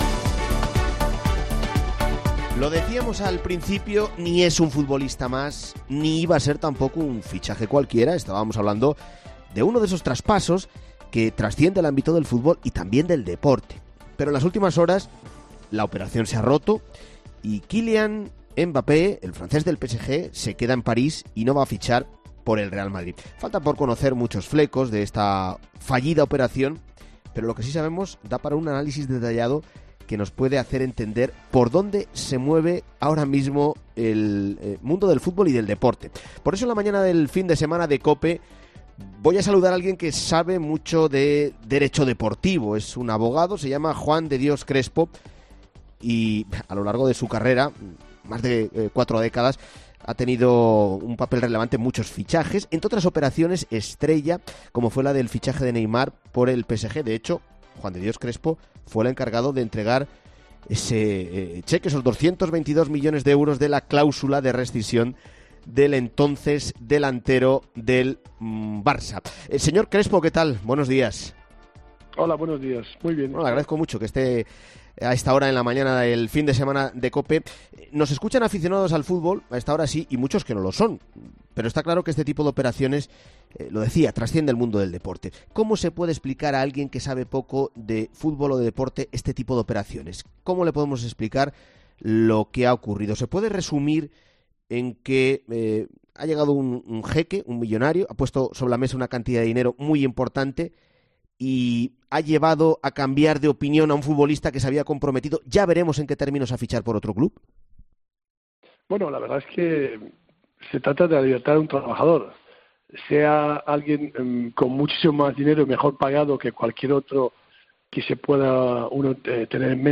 Un abogado deportivo, sobre Mbappé: "Sería una barbaridad que hubiera firmado un precontrato con el Madrid"